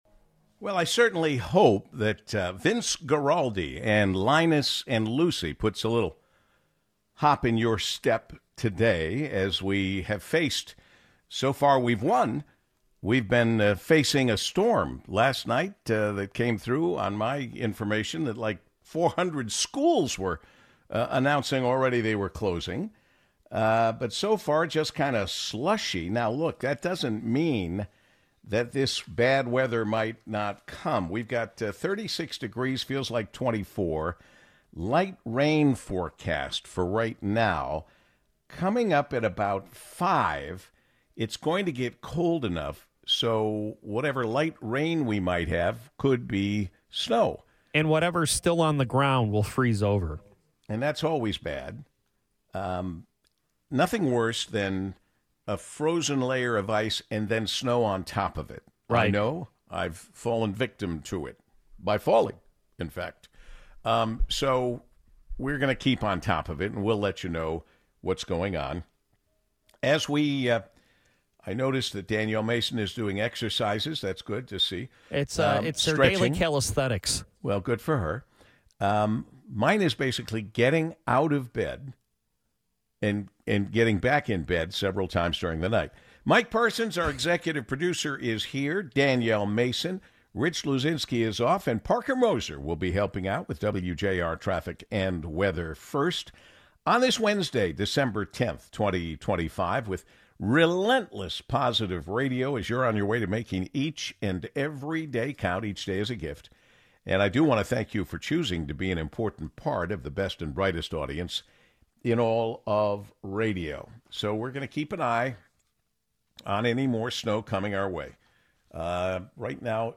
Accessible, informative, balanced, and bold, with diverse voices and thought-provoking questions that bring it all into Focus.